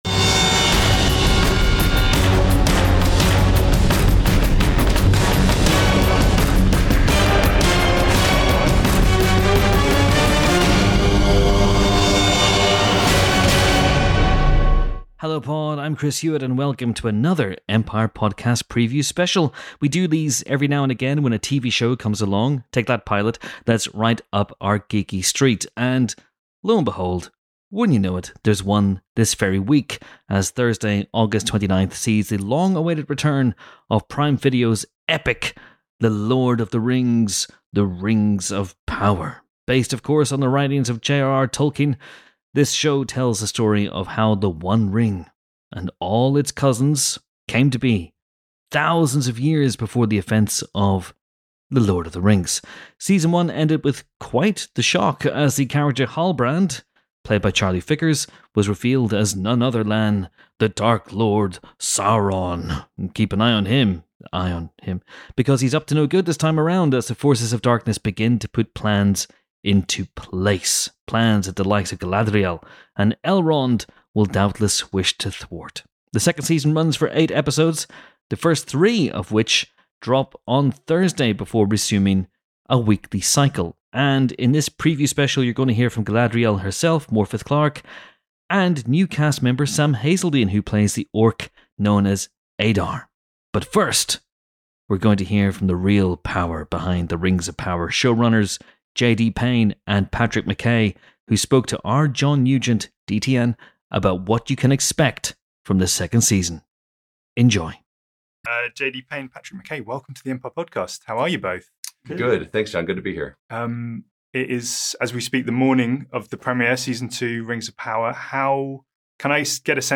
(That interview has slight sound issues, by the way)